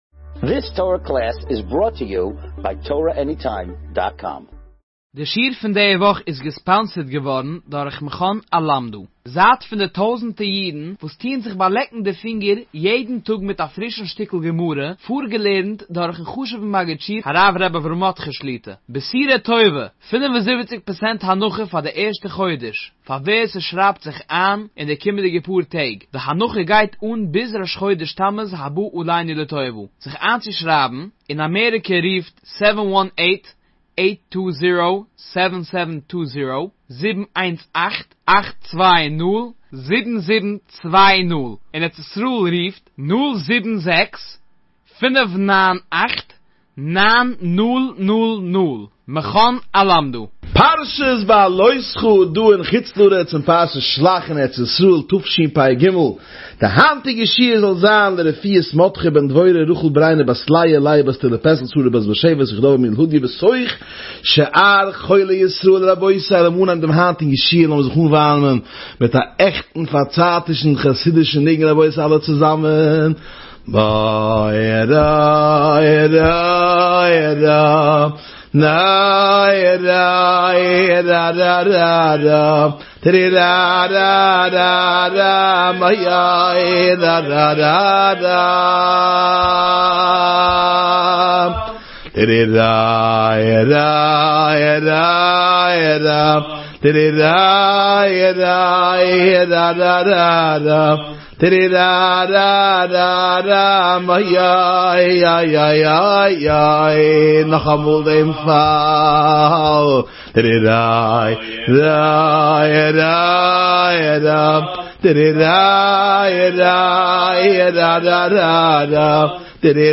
הגיע שיעור חדש